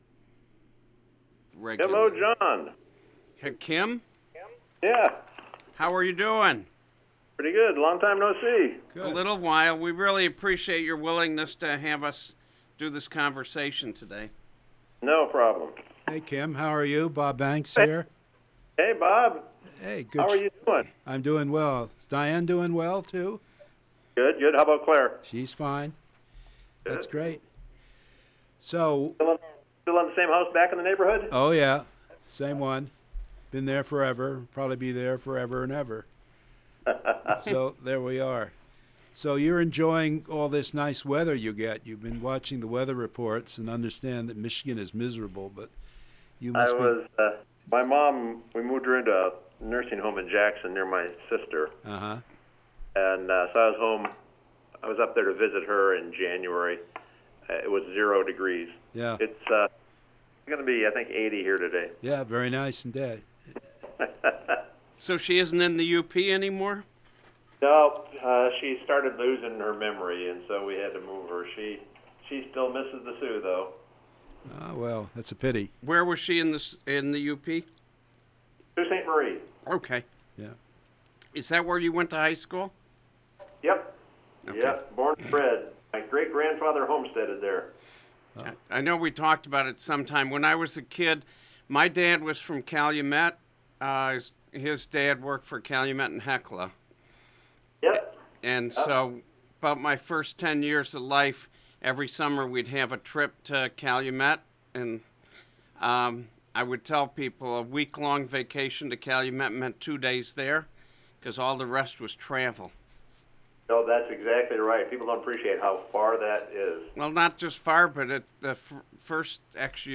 Interview of Kim Wilcox, former Michigan State University Provost on the MSU Faculty Grievance Policy (FGP) and the Faculty Grievance Official (FGO)